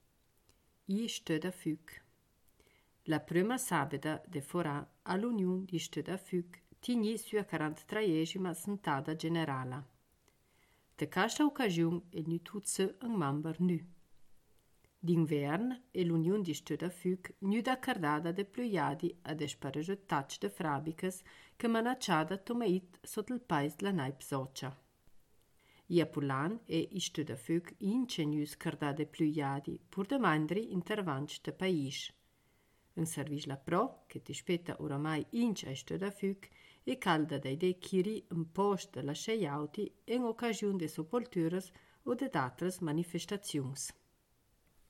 Ladino badiota